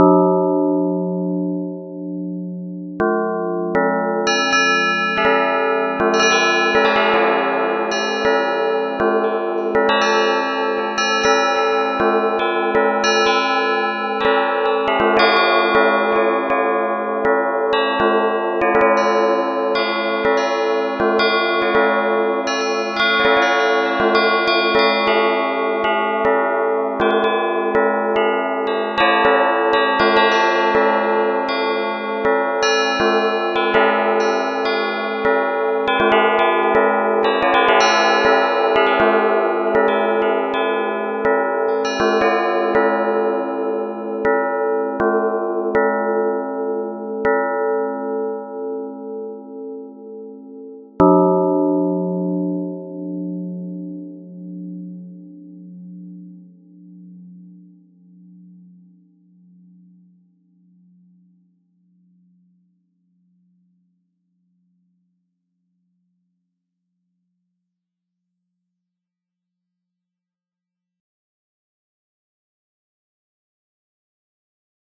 • example/multiplication_bells.f90: a demo heavily using the add_bell() signal added in src/signals.f90. It is based on Jean-Claude Risset’s bell signal #430 (in his 1969 report) with 11 partials (bells have no harmonics but partials). Some partials are very close to simulate the beating that can be heared when a bell is ringing (due to the fact that real bells are never perfectly symetrical).
multiplication_bells.ogg